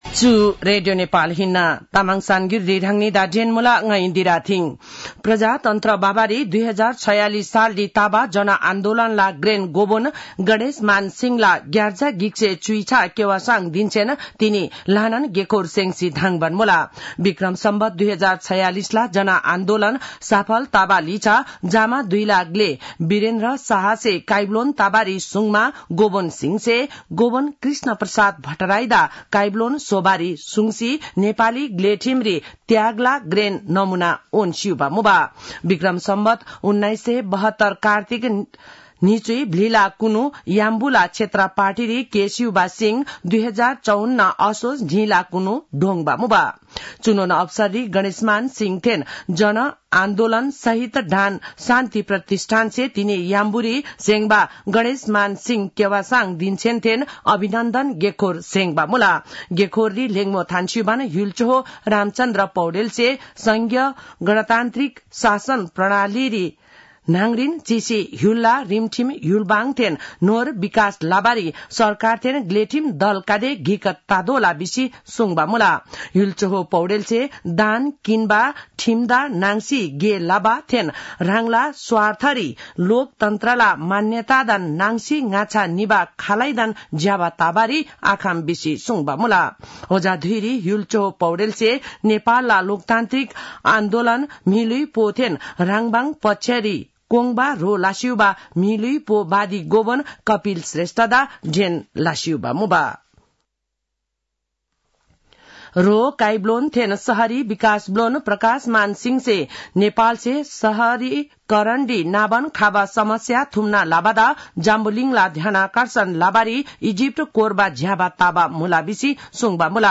तामाङ भाषाको समाचार : २५ कार्तिक , २०८१